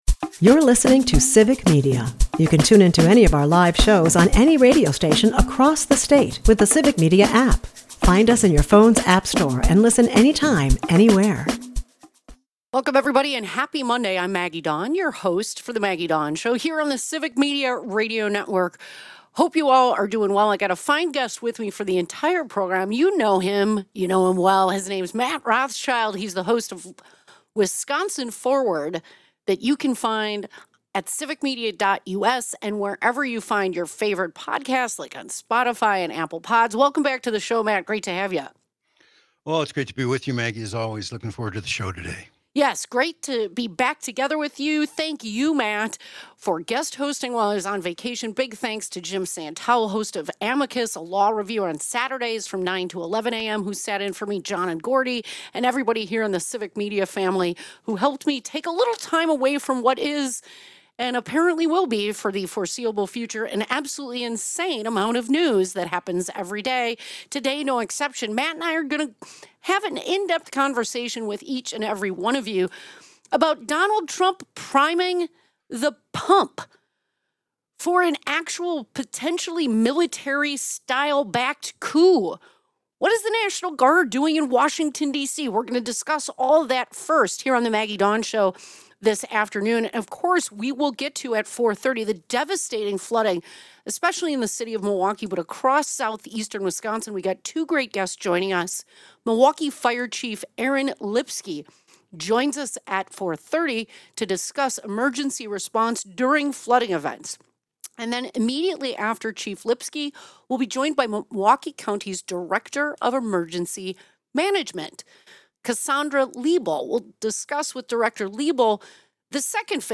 They unpack the misleading crime stats, the racist undertones behind his rhetoric, and what it means for Wisconsin. Milwaukee Fire Chief Aaron Lipski and Emergency Management Director Cassandra Libal join to share the city’s heroic flood response and stress why calling 211 is critical for damage assessment.